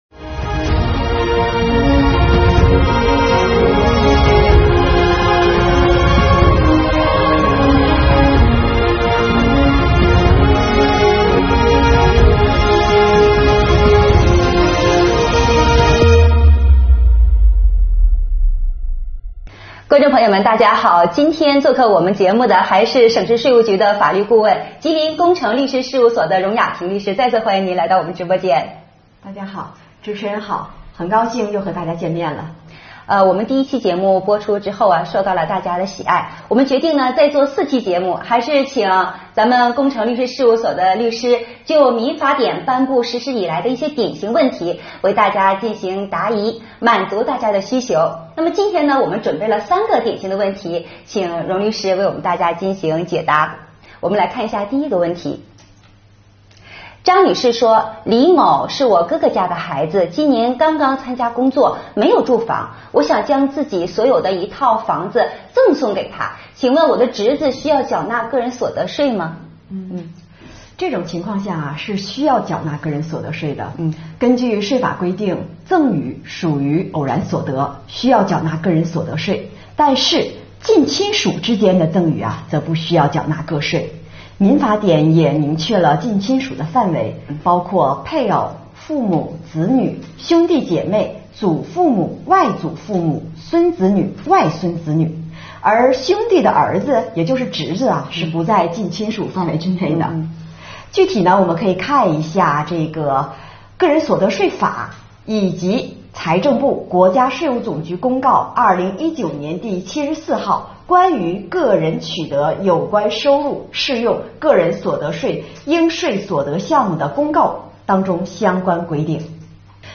2021年第4期直播回放：《民法典》热点问题解读系列之二